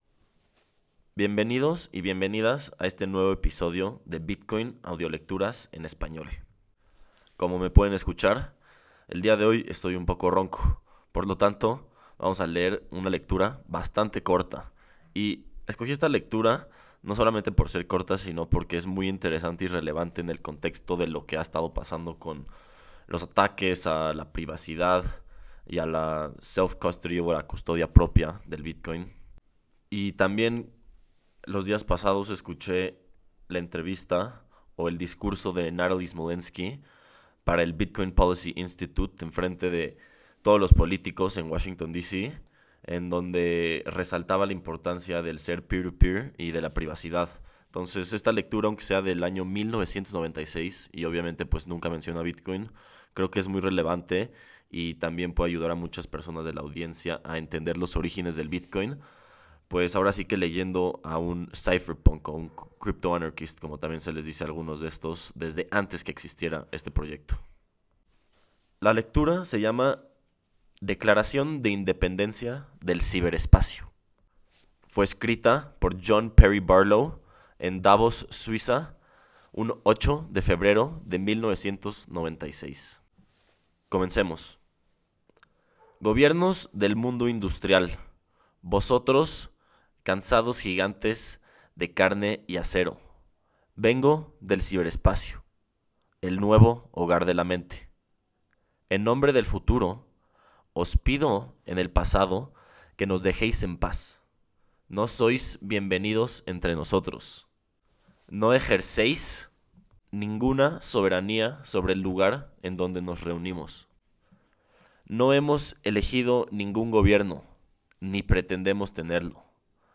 Esta tradicional y controversial lectura del año 1996, nos lleva a los orígenes de Bitcoin y los cypherpunks.